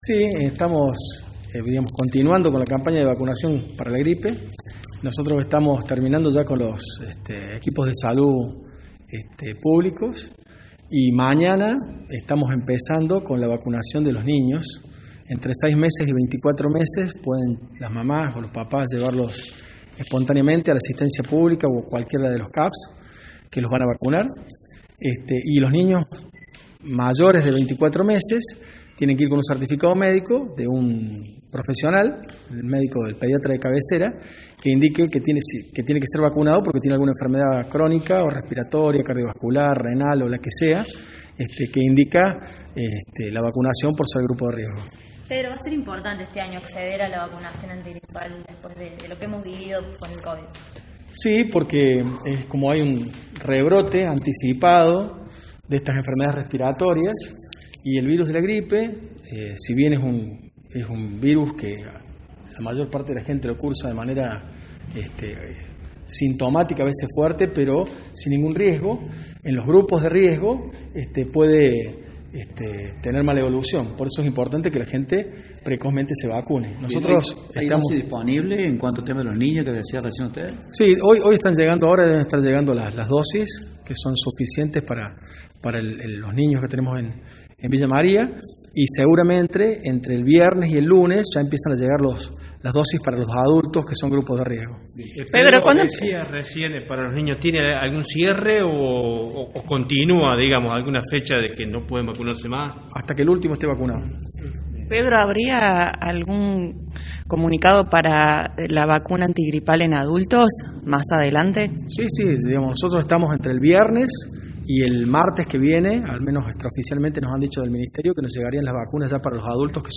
AUDIO – DR. PEDRO TRECCO, SEC. DE SALUD PÚBLICA MUNICIPAL